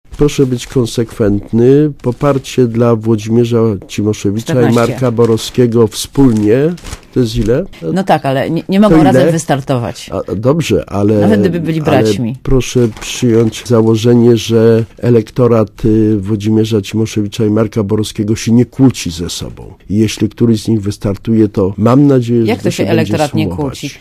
Mówi Krzysztof Janik